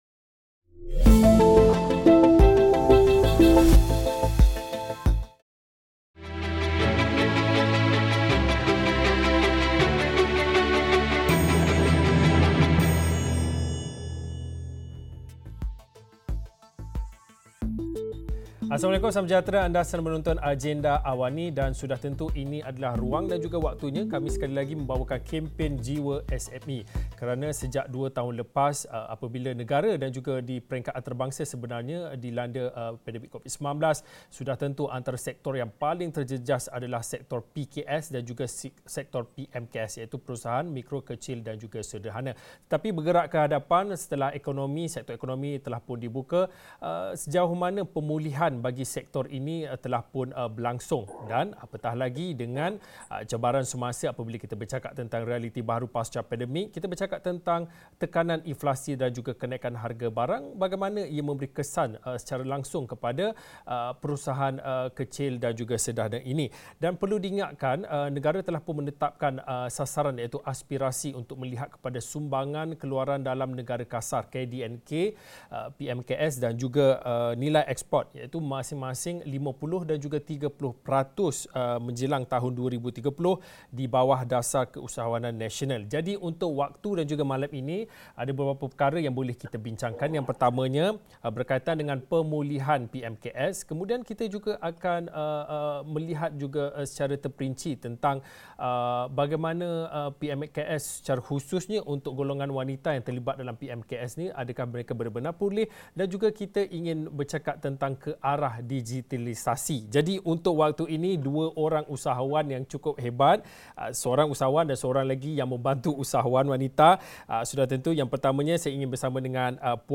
Cabaran wanita dalam keusahawanan digital – realiti kini berbeza, bagaimana adaptasi digital dapat membantu melonjak perniagaan khususnya Perusahaan Kecil dan Sederhana (PKS)? Diskusi 9 malam.